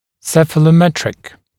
[ˌsefələˈmetrɪk][ˌсэфэлэˈмэтрик]цефалометрический